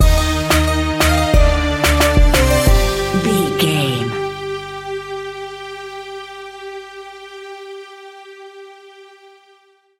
Aeolian/Minor
World Music